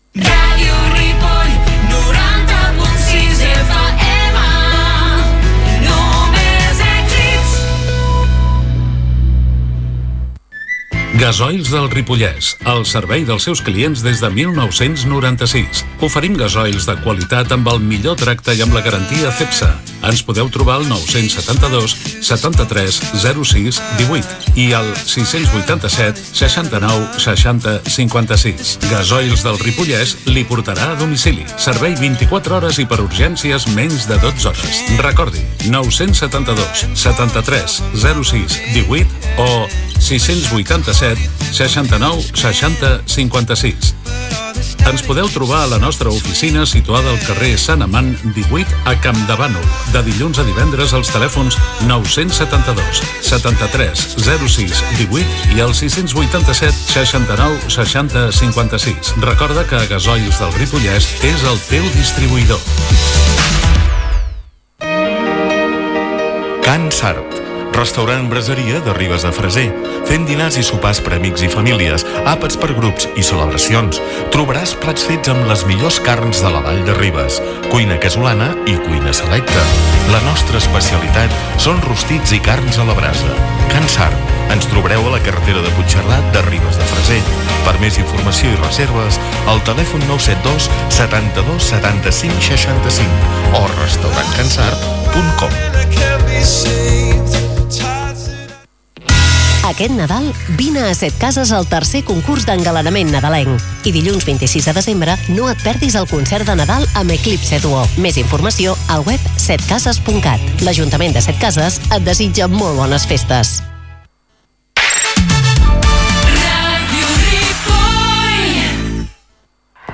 Indicatiu de l'emissora, publicitat, indicatiu i tema musical.
FM